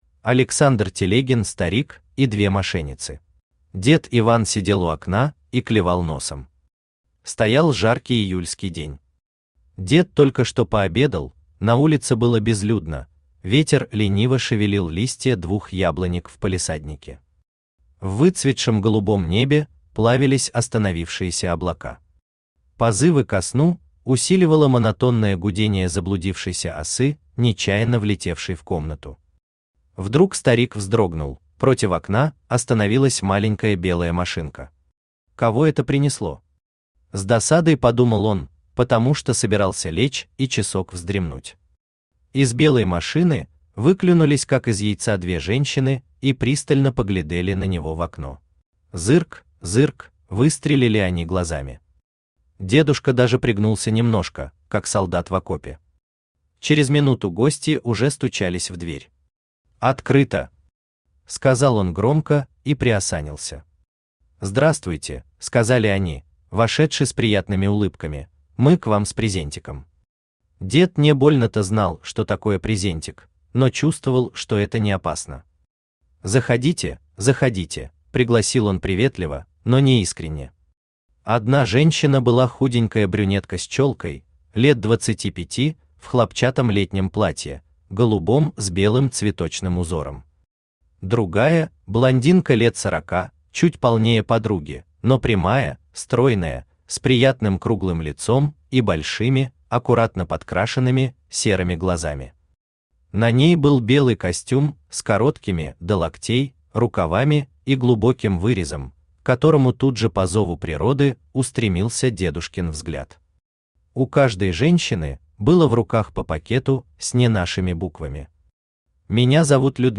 Аудиокнига Старик и две мошенницы | Библиотека аудиокниг
Aудиокнига Старик и две мошенницы Автор Александр Александрович Телегин Читает аудиокнигу Авточтец ЛитРес.